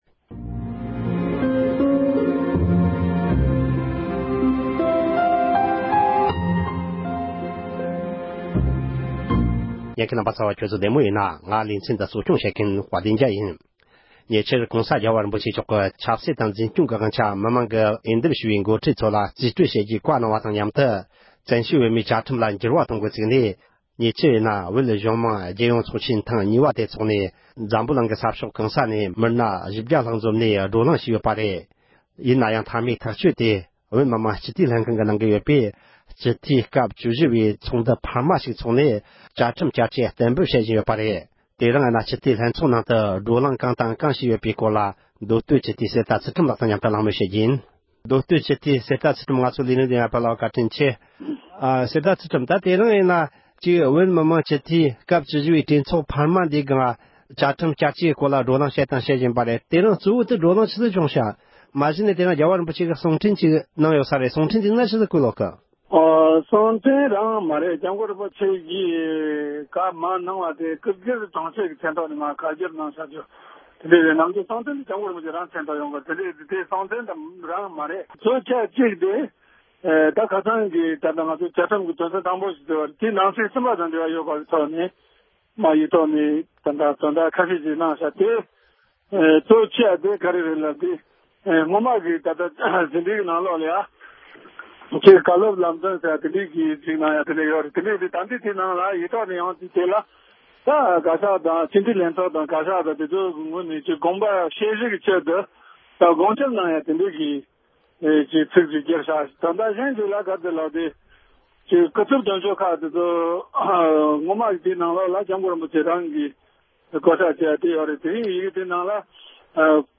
བཙན་བྱོལ་བོད་མིའི་བཅའ་ཁྲིམས་ལ་བསྐྱར་བཅོས་གཏན་འབེབས་བྱེད་བཞིན་ཡོད་པའི་ཐད་མི་སྣ་ཁག་དང་ལྷན་དུ་གླེང་མོལ་ཞུས་པ།